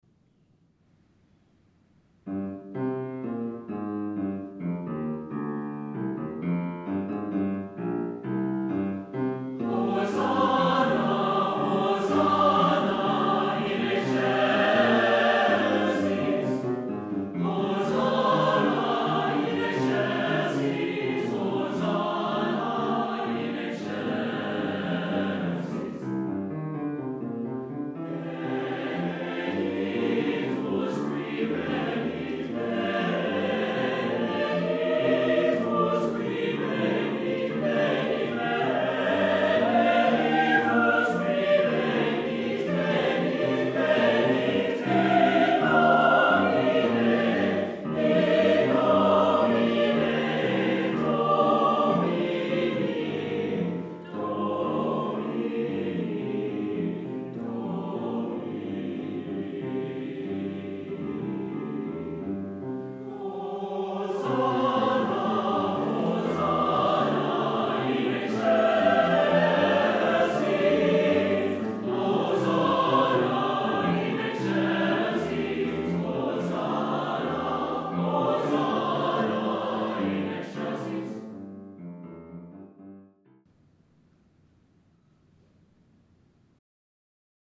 lively Palm Sunday work with syncopated bass accompaniment